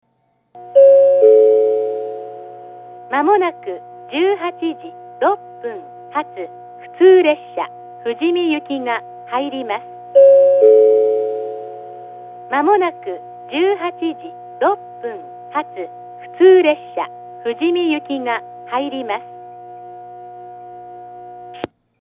発車ベルはありませんが、接近放送があります。
２番線上り接近予告放送 18:06発普通富士見行の放送です。